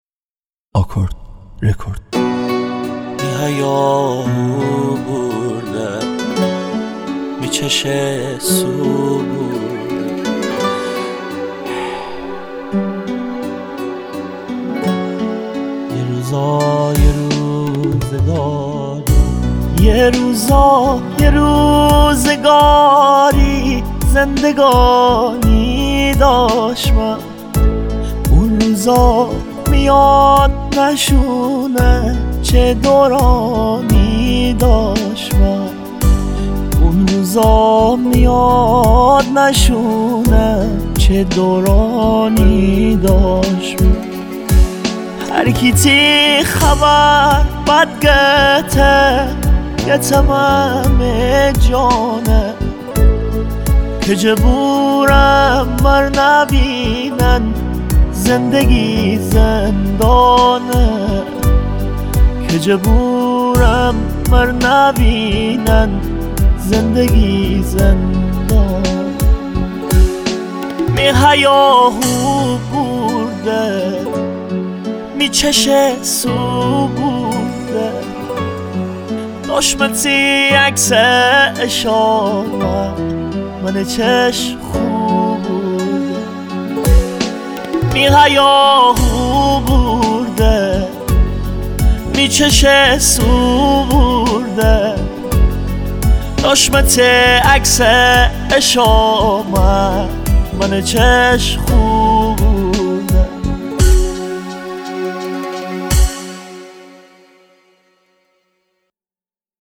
آهنگ جدید فارسی و محلی